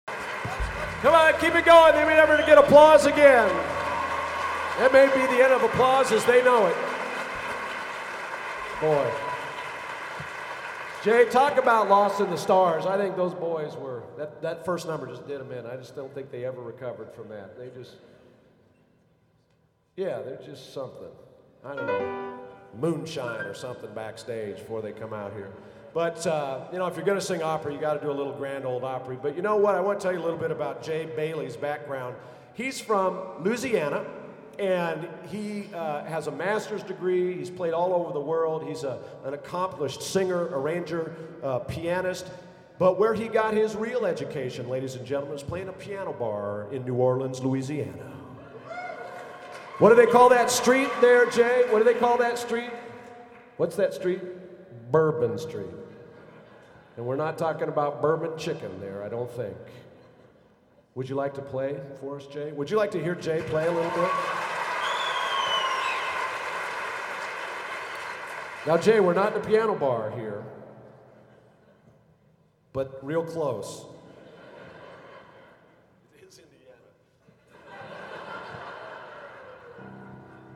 Location: Carmel High School, Carmel, IN
Genre: Broadway Instrumental | Type: Director intros, emceeing